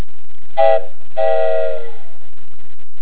trainMono8.wav